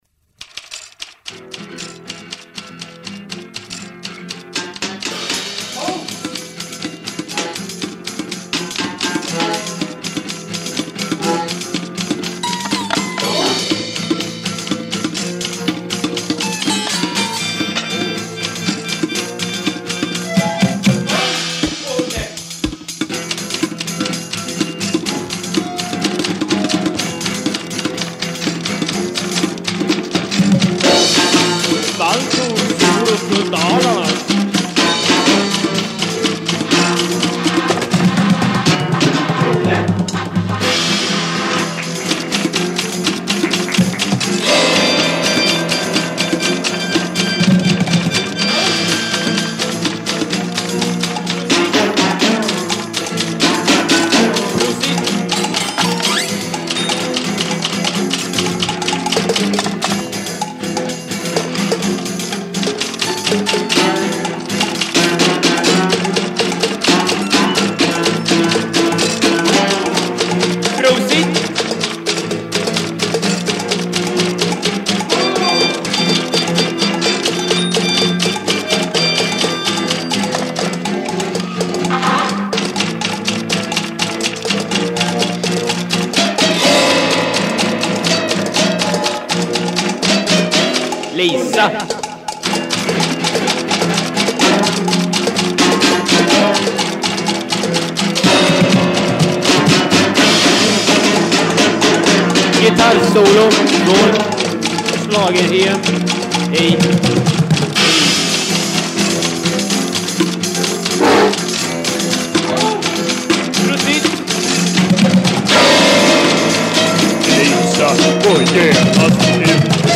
Percussion instruments
Guitar, Voice, Various instruments